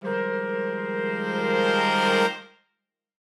Index of /musicradar/gangster-sting-samples/Chord Hits/Horn Swells
GS_HornSwell-Fmin+9sus4.wav